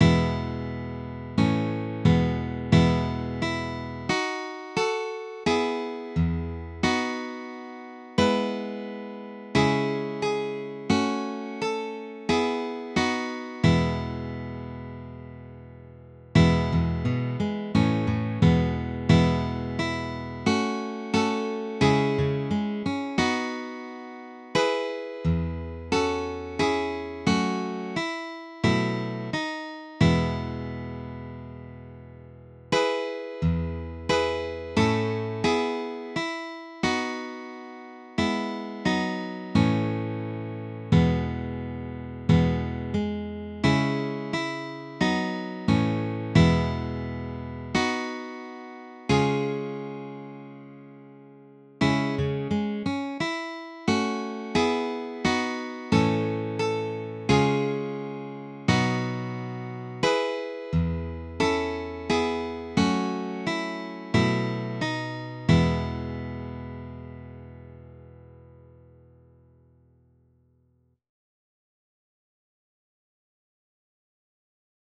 DIGITAL SHEET MUSIC - FLATPICK/PLECTRUM GUITAR SOLO
Sacred Music, Preludes, Graduals, and Offertories
Dropped D tuning